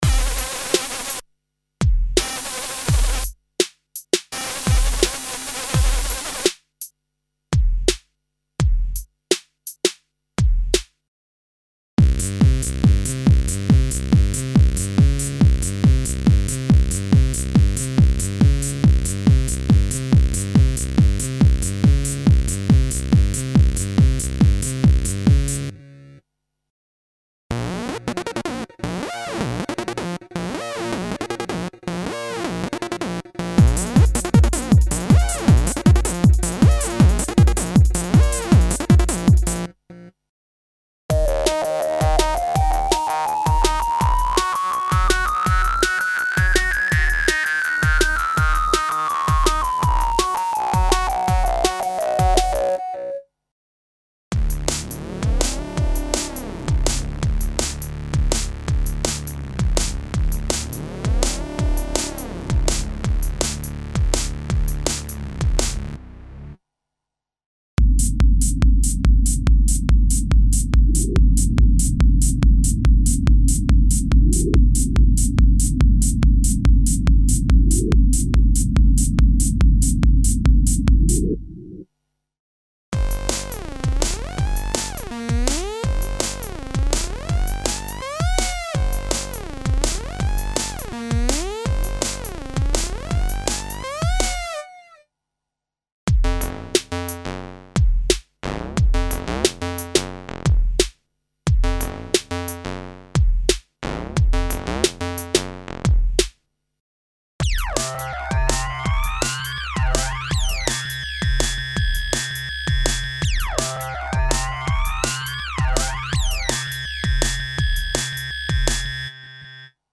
Original collection of oscillator syncs and synth effects for a wide variety of music styles (Techno, House, Trance, Jungle, Rave, Break Beat, Drum´n´Bass, Euro Dance, Hip-Hop, Trip-Hop, Ambient, EBM, Industrial, etc.).
Info: All original K:Works sound programs use internal Kurzweil K2661 ROM samples exclusively, there are no external samples used.